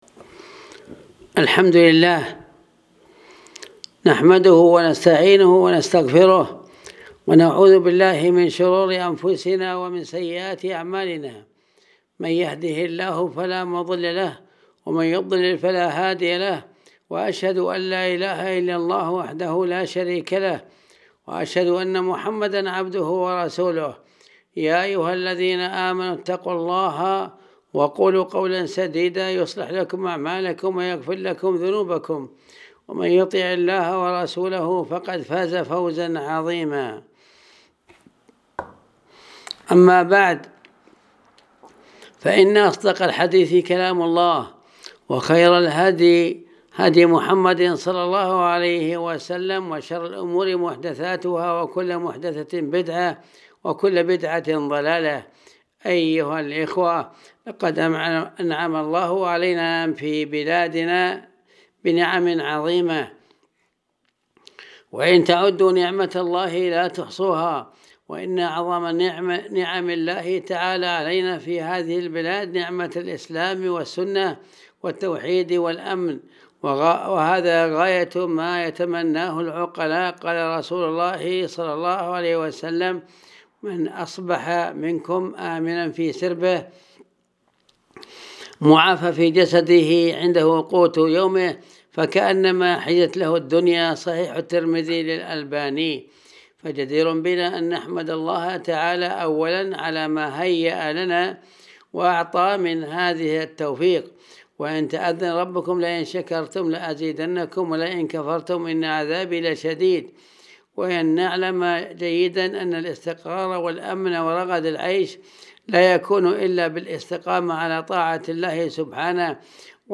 الخطب